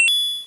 pickup-coin.wav